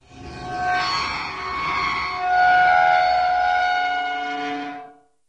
Metal Chalkboard Squeal Slow, Downward